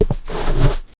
Close-range, realistic game UI interaction sound. 0:10 bunch of very small rocks (2 cm) getting grinded with card under a money bill on flat surface 0:10 A realistic sound of a wooden matchbox sliding open and closed. The sound should capture the friction of cardboard and wood rubbing, continuous and loopable.